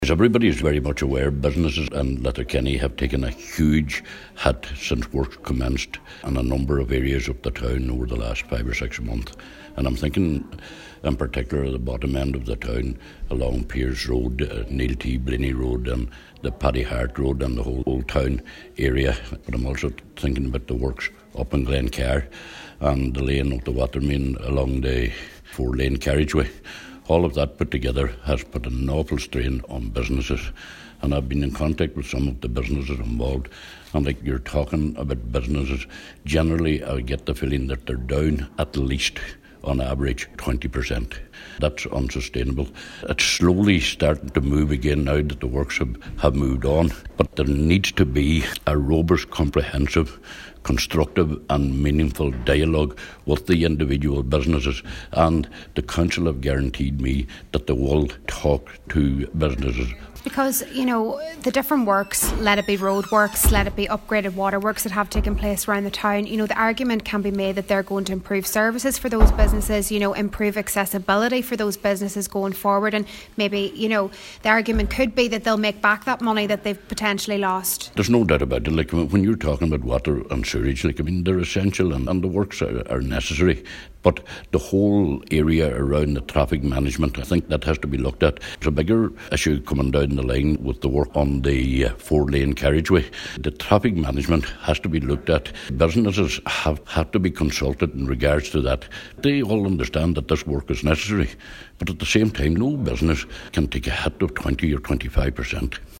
But Cllr Donal Coyle says this is unfair and is asking them to reconsider………….